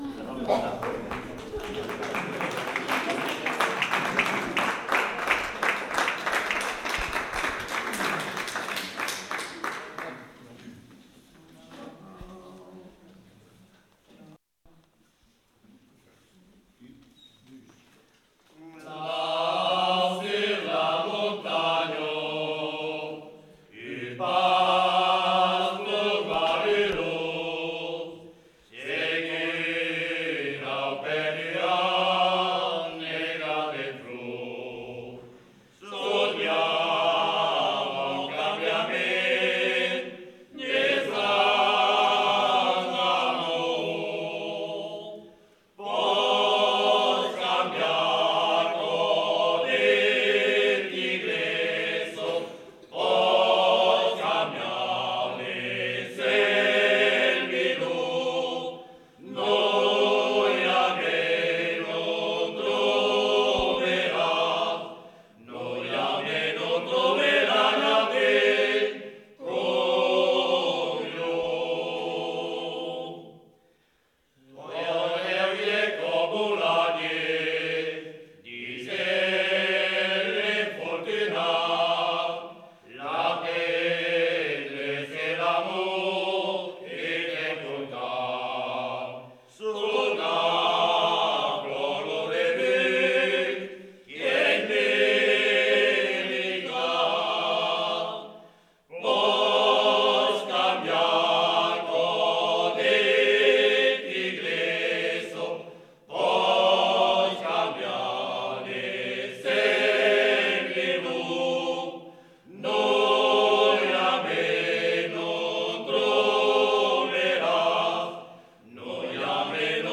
Eths amassats de Bigòrra (ensemble vocal)
Aire culturelle : Bigorre
Lieu : Ayros-Arbouix
Genre : chant
Type de voix : voix d'homme
Production du son : chanté
Descripteurs : polyphonie